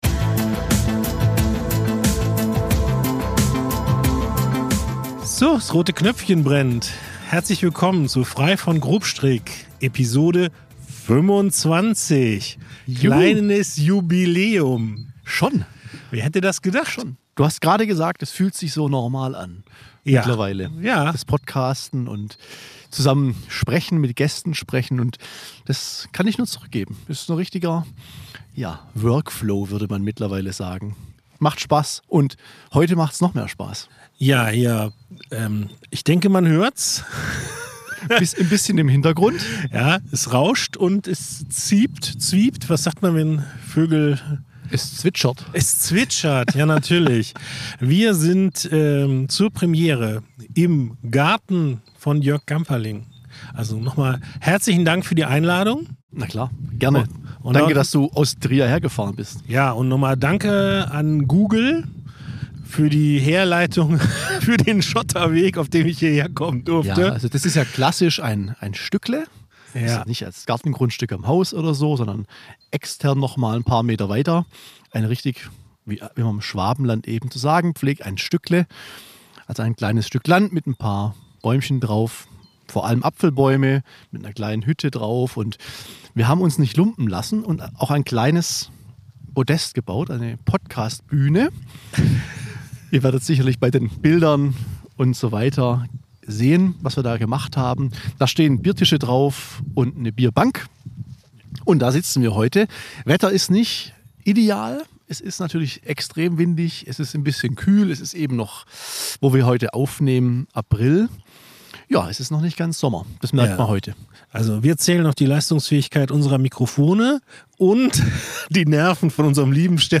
Aufgenommen wird unter freiem Himmel mit Vogelgezwitscher – ein Podcast im Grünen.